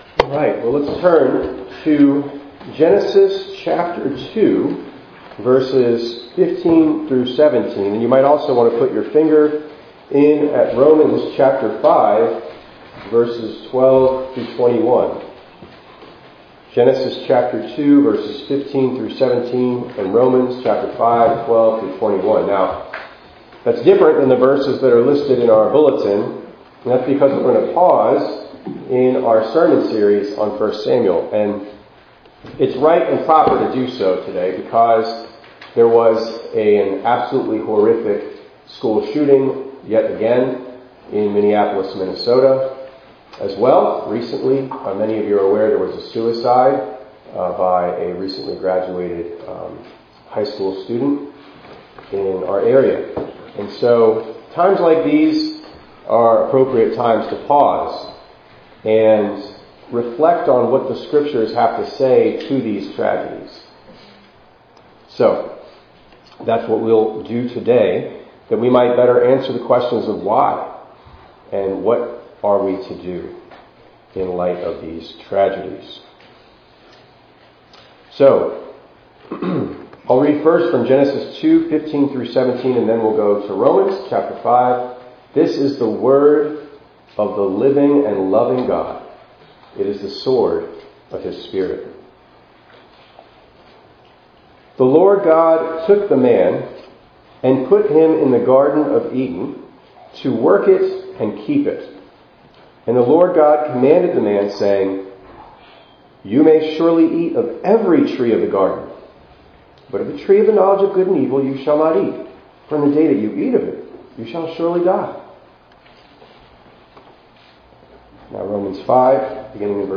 8_31_25_ENG_Sermon.mp3